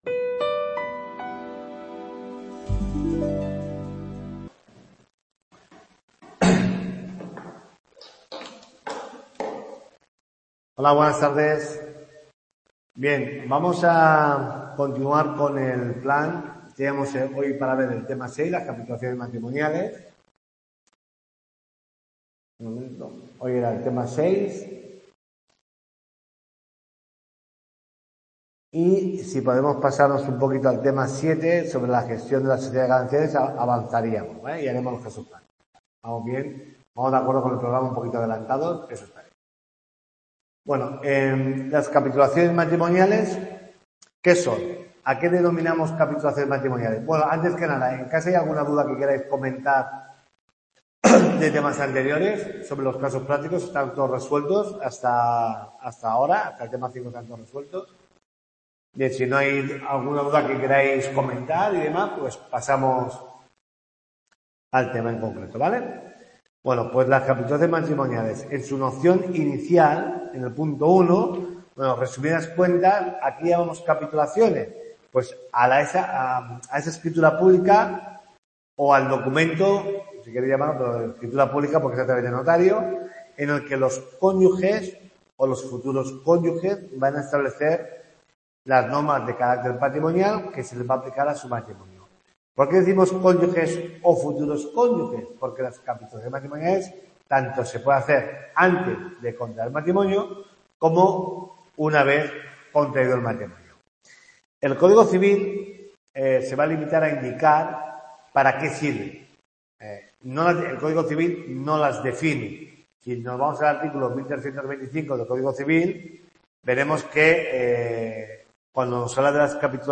tutoria 5